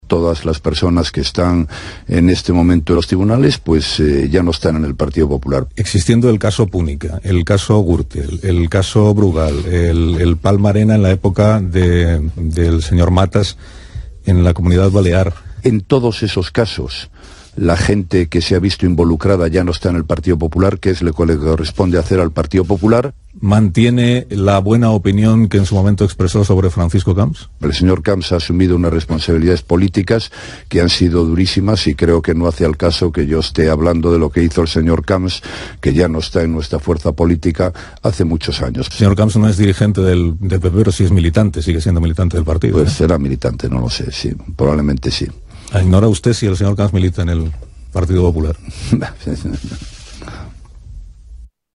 Rajoy preguntat a Onda cero sobre si hauria de facilitat el retorn de Puigdemont perquè sigui investit